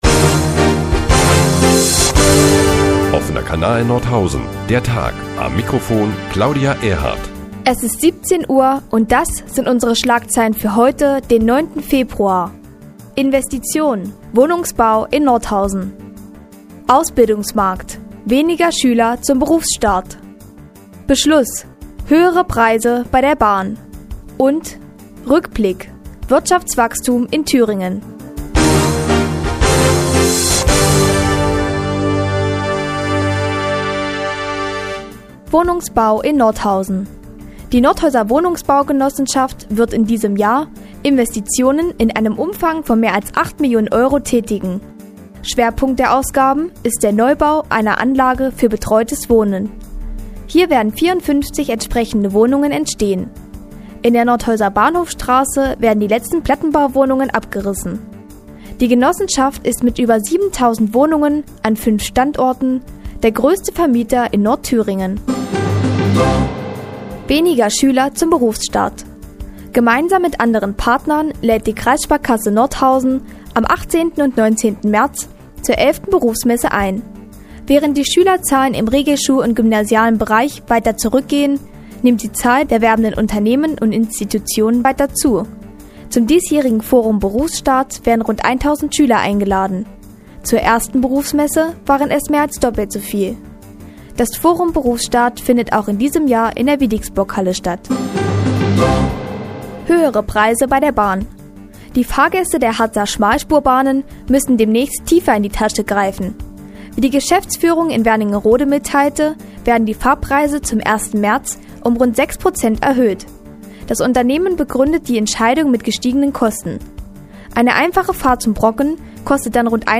Die tägliche Nachrichtensendung des OKN ist nun auch in der nnz zu hören. Heute unter anderem mit dem Forum "Berufsstart" und gestiegenen Preisen bei der Bahn.